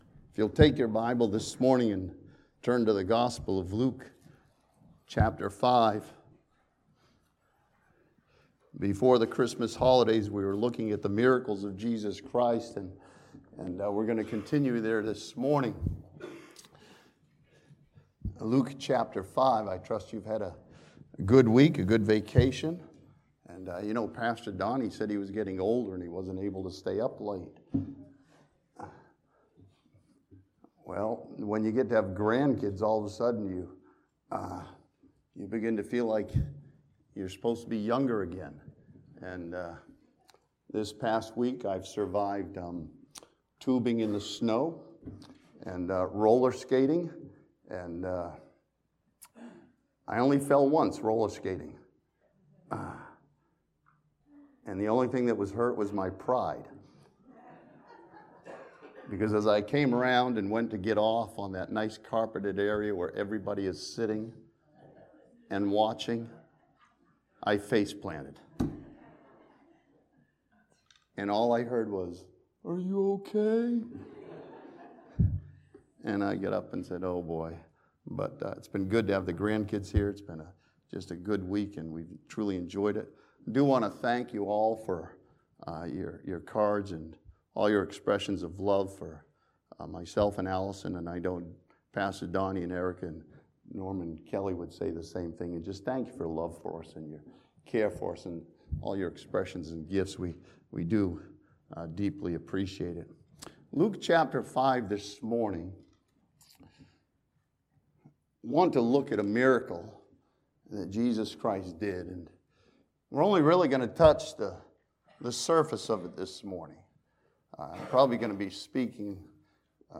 This sermon from Luke chapter 5 sees that healing of a man full of leprosy as a miracle of hope for every believer.